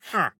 Minecraft Version Minecraft Version 1.21.4 Latest Release | Latest Snapshot 1.21.4 / assets / minecraft / sounds / mob / wandering_trader / haggle3.ogg Compare With Compare With Latest Release | Latest Snapshot
haggle3.ogg